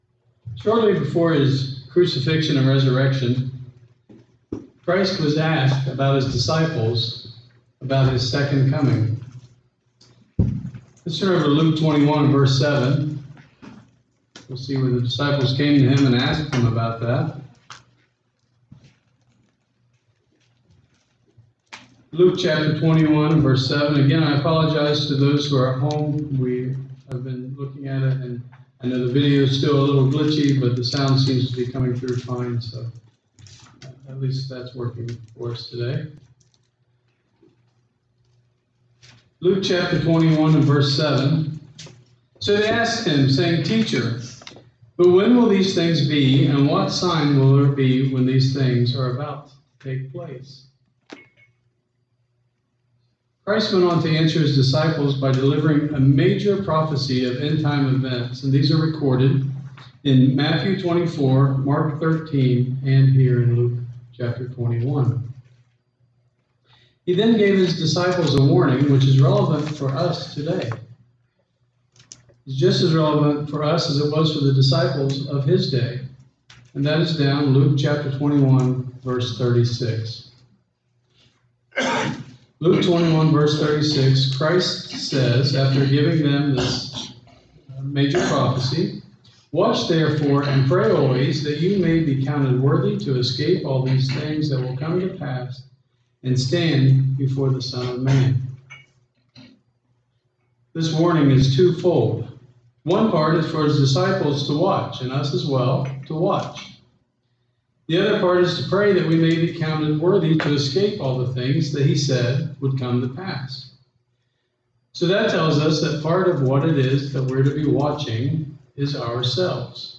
Christ gave many prophecies of end time events. In this sermon, we will examine some of the events that must take place before Christ will return.
Given in Dayton, OH